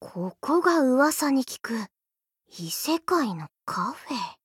BA_V_Ruiko_Cafe_monolog_4.ogg